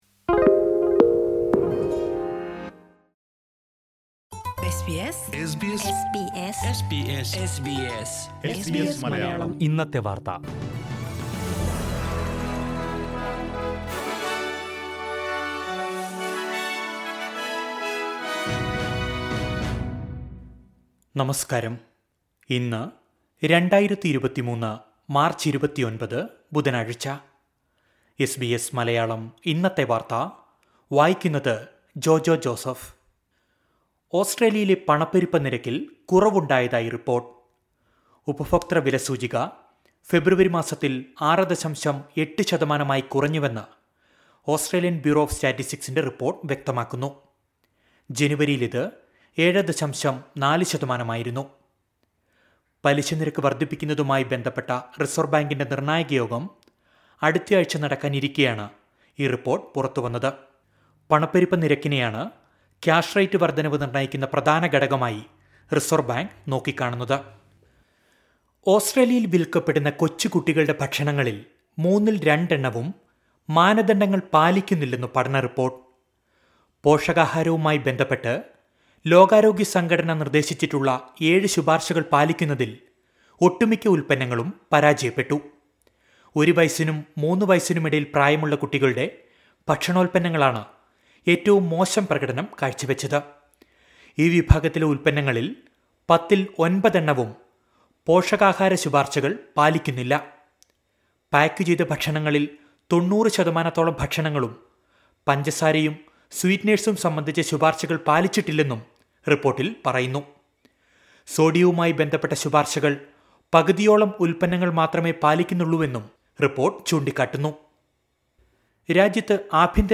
2023 മാർച്ച് 29ലെ ഓസ്ട്രേലിയയിലെ ഏറ്റവും പ്രധാന വാർത്തകൾ കേൾക്കാം...